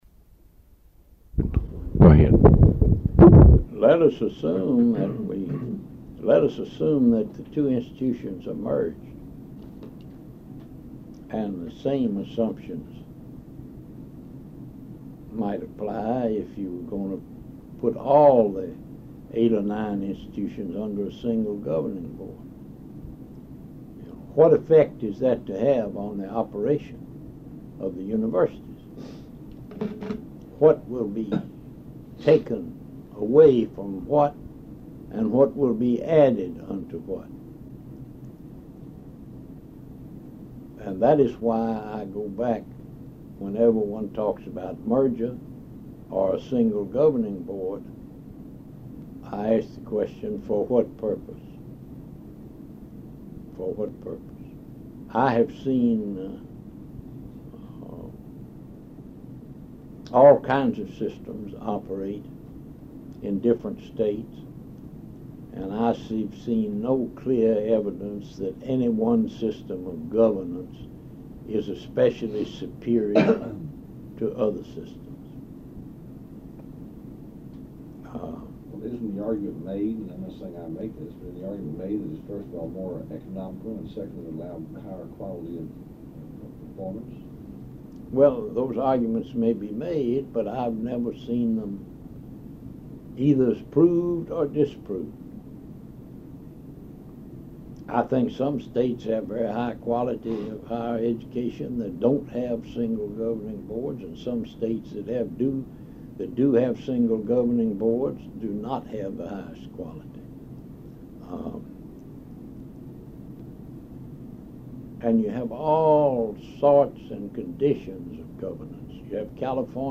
Oral History Interview with Edward F. Prichard, Jr., October 31, 1984 Part 2